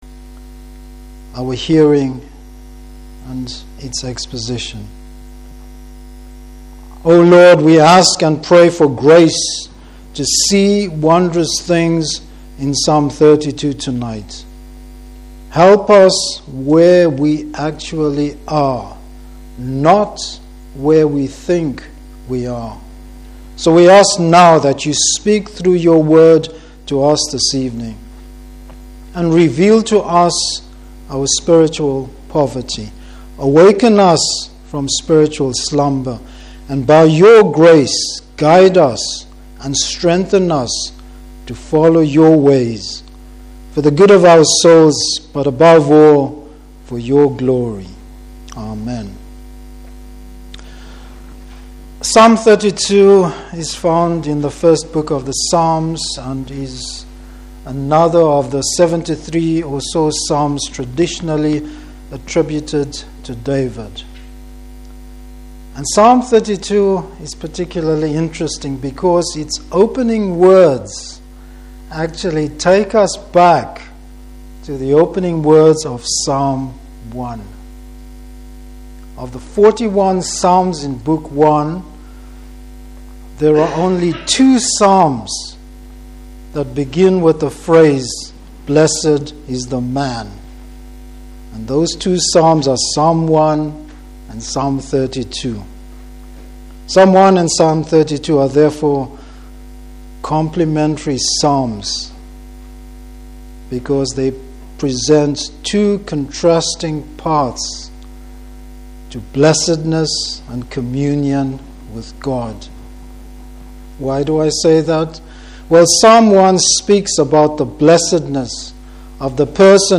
Service Type: Evening Service David’s relief and joy when he experiences the Lord’s forgiveness.